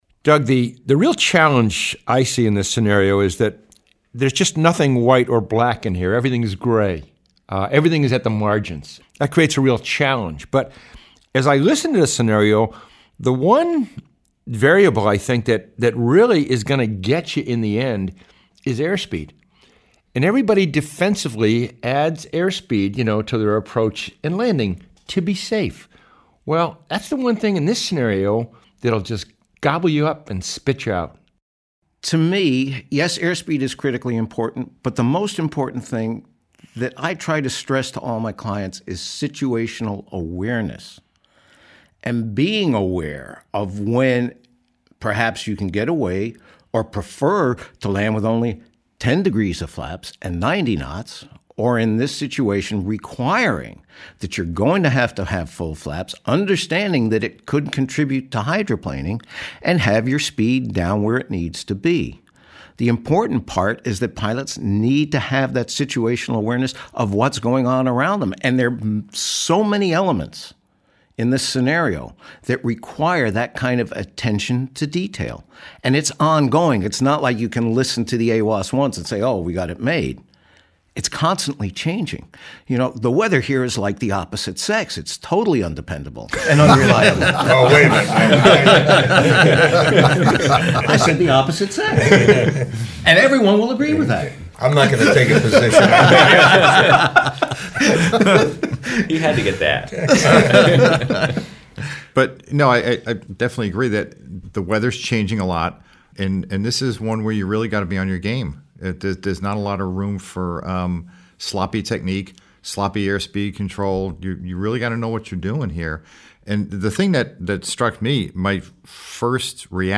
The Lights at Columbia County_roundtable.mp3